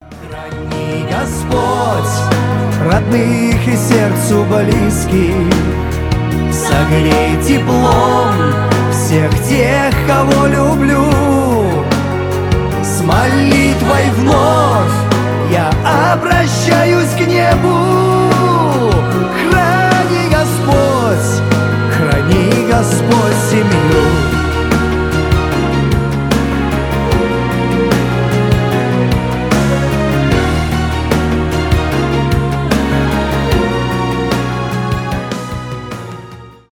душевные
шансон